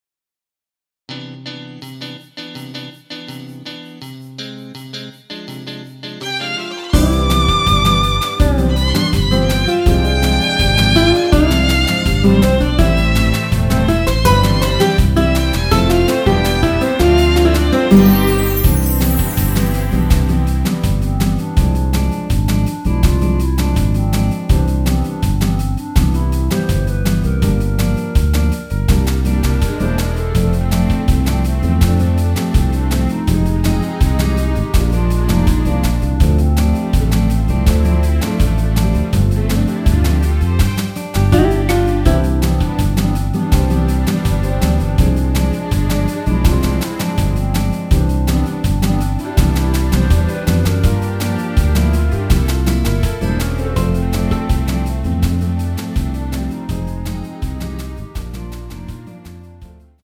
원키에서(-1)내린 멜로디 포함된 MR입니다.
◈ 곡명 옆 (-1)은 반음 내림, (+1)은 반음 올림 입니다.
앞부분30초, 뒷부분30초씩 편집해서 올려 드리고 있습니다.